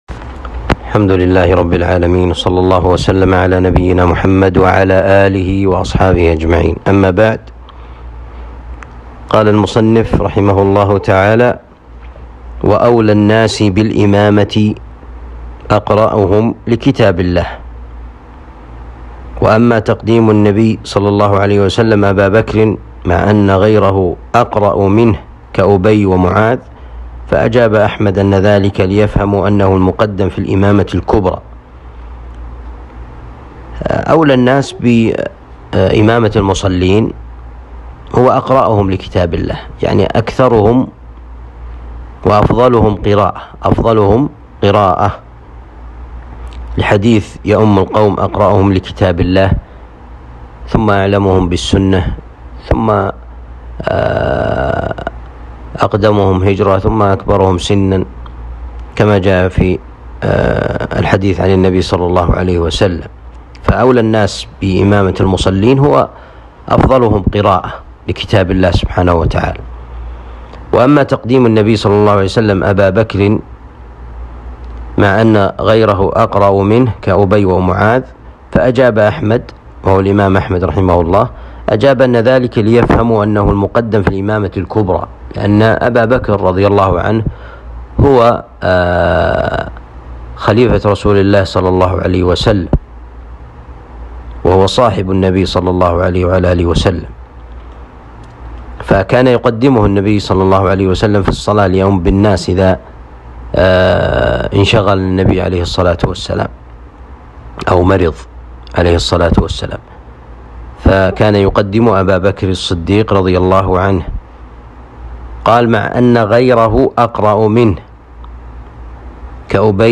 الدروس شرح كتاب آداب المشي إلى الصلاة